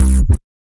神经低音刺痛
描述：脂肪
标签： 运动 回响贝斯 再采样 低音 神经 变压器
声道立体声